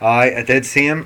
Examples of Northern Ireland English
//ɑɪ/ɑɪ dɪd siː ɪm//
H-dropping is common in all varieties in the pronunciation of pronouns like him in rapid, casual speech.